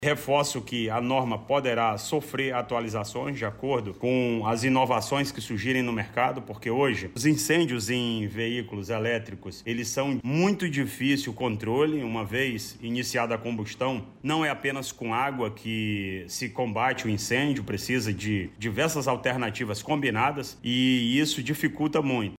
Ainda de acordo com o comandante-geral, incêndios em carros elétricos são de difícil controle, por isso a norma pode sofrer alterações com as mudanças no segmento.